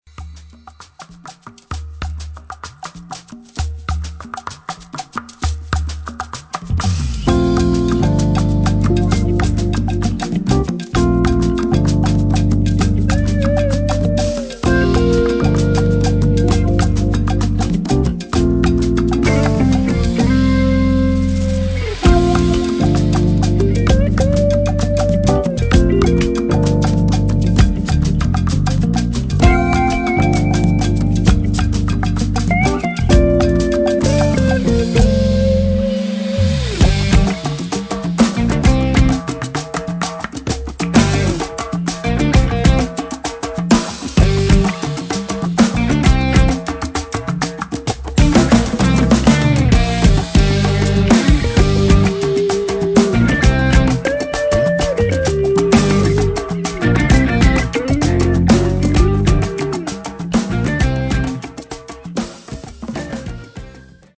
زنگ موبایل شاد